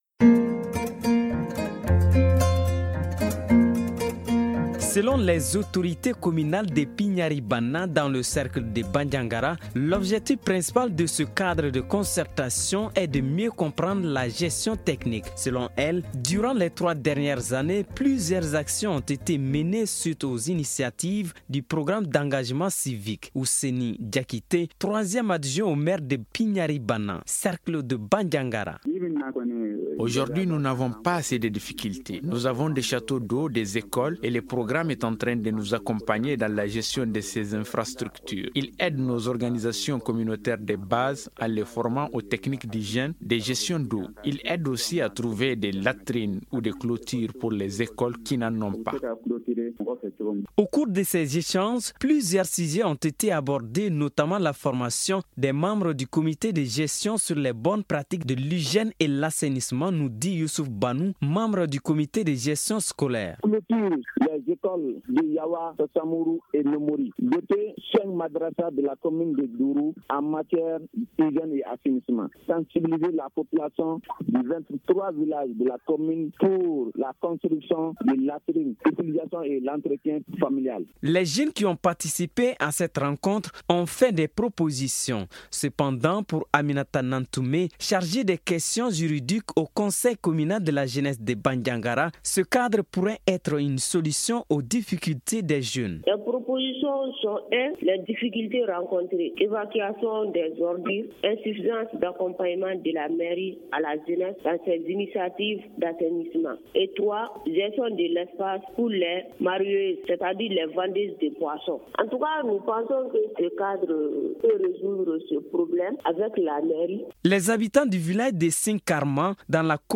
Magazine en français: Télécharger